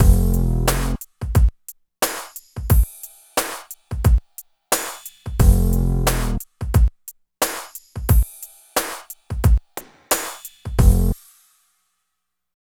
16 LOOP   -R.wav